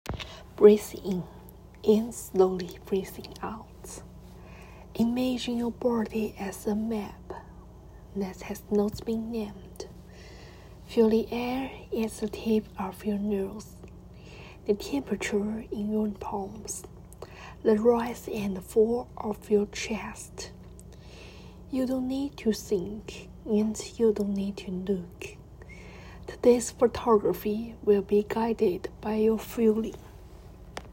Step 1: Meditation (1 mins)